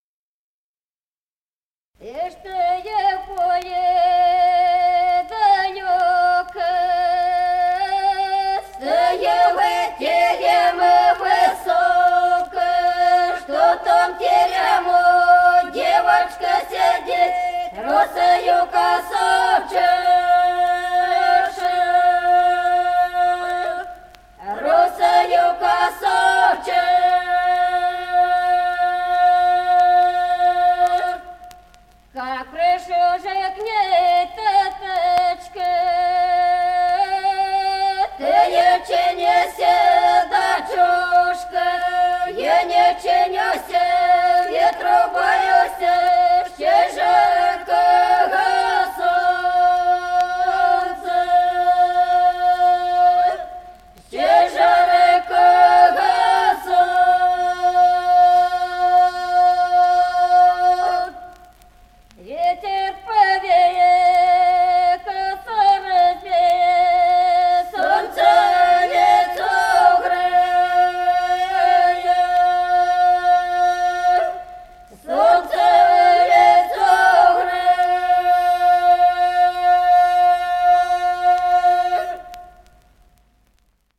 Народные песни Стародубского района «И чтой в поле далёко», свадебная.
Записано в Москве весной 1966 г., с. Остроглядово.